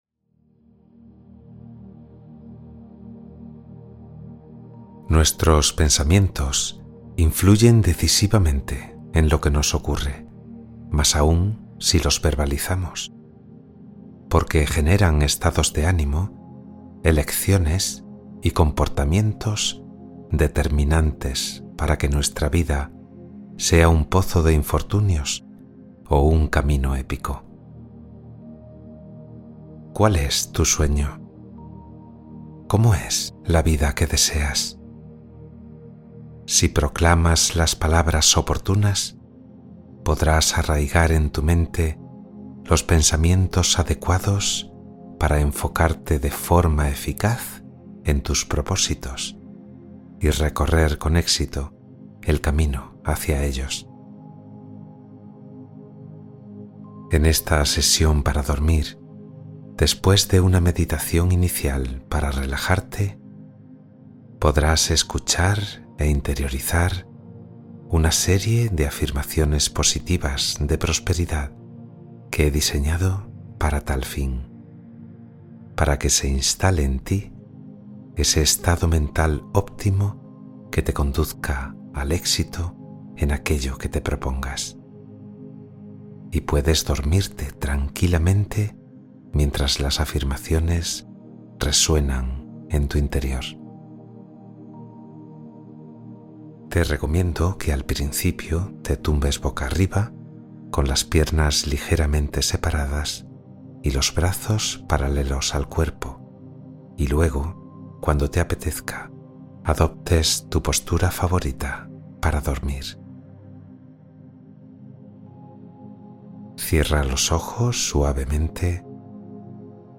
Meditación para dormir con afirmaciones de éxito y prosperidad